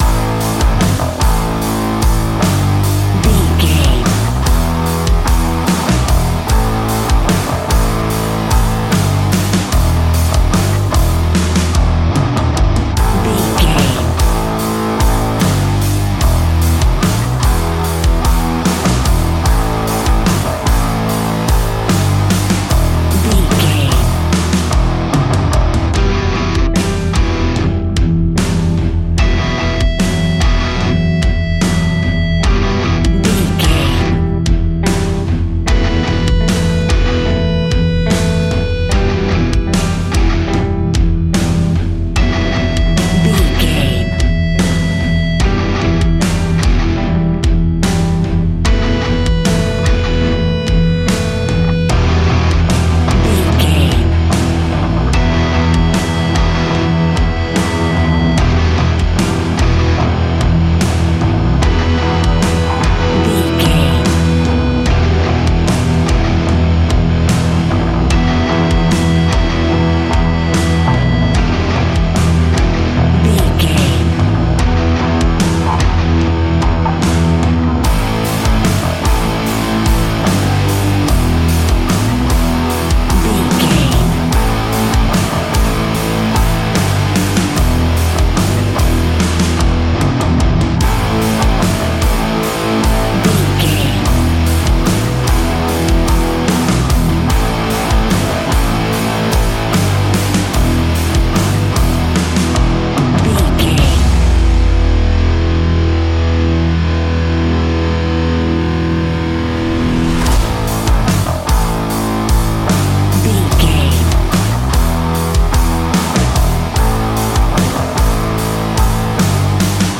Ionian/Major
E♭
hard rock